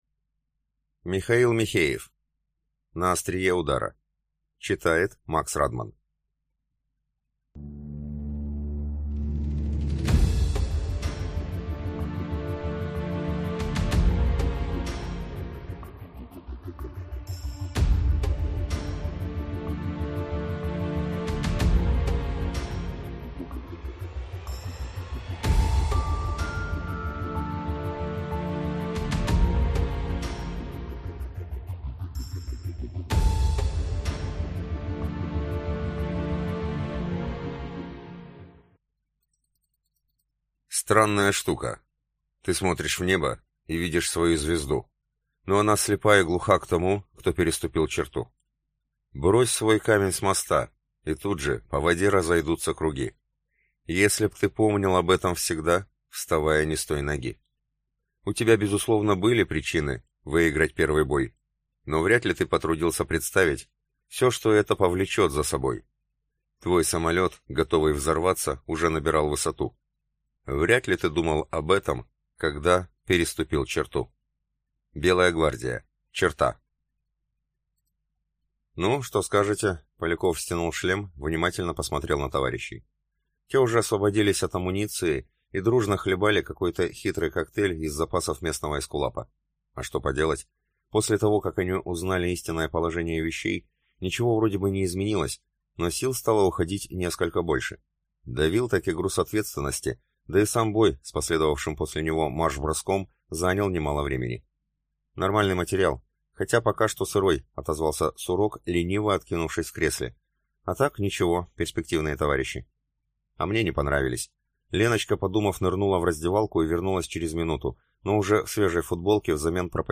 Aудиокнига На острие удара